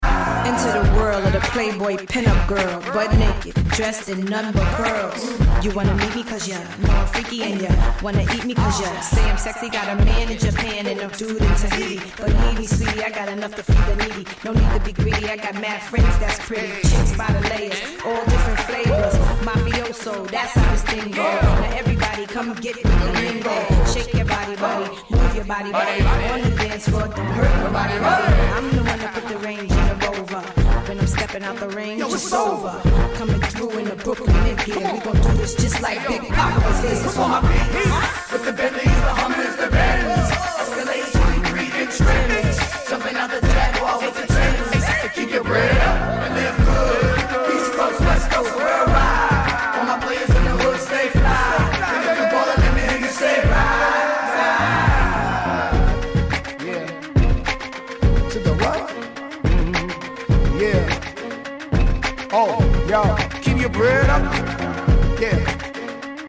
A true early-2000s hip-hop moment.